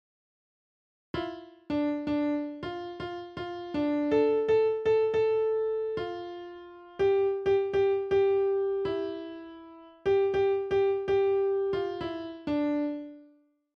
Co za utwór, może to rock, pop - Rock / Metal